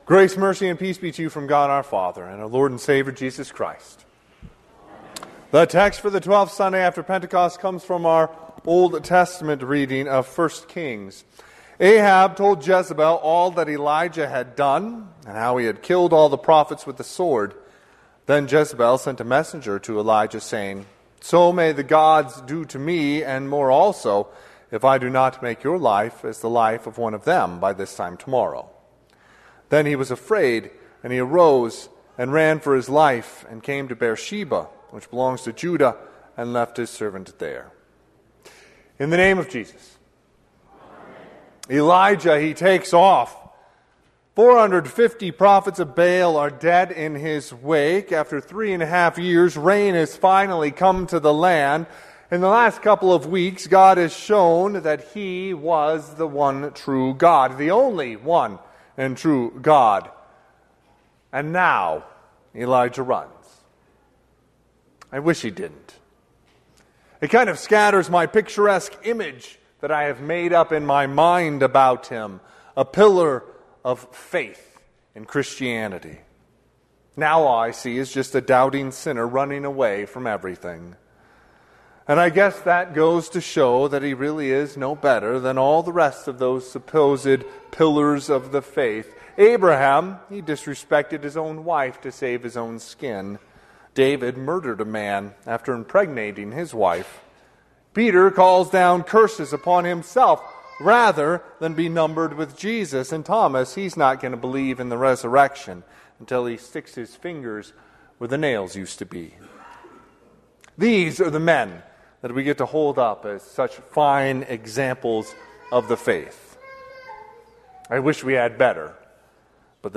Sermon - 8/11/2024 - Wheat Ridge Lutheran Church, Wheat Ridge, Colorado
Twelfth Sunday after Pentecost